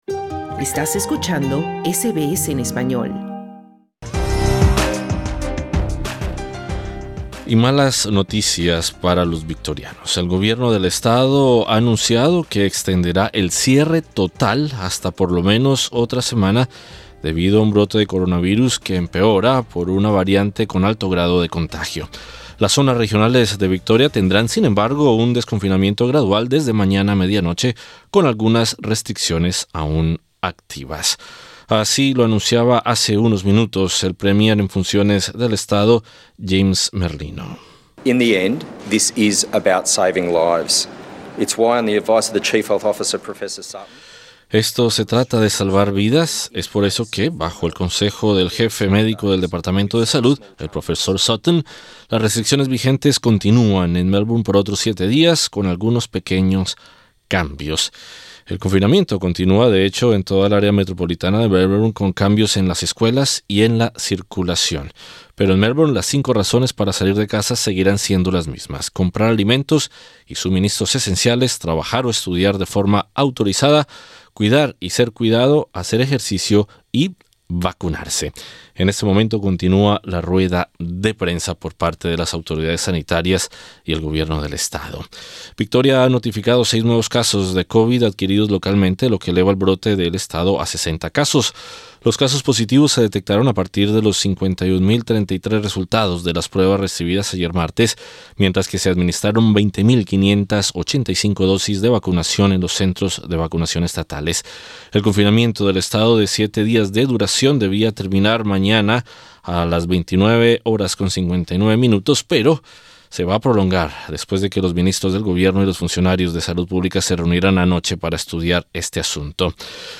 Dos dueños de negocios de comida hispana en Melbourne hablan con SBS Spanish sobre la “desastrosa” realidad que enfrentan por la caída de ventas, tras el cuarto cierre de Victoria impuesto por el Gobierno estatal para contener una nueva variante de la COVID-19.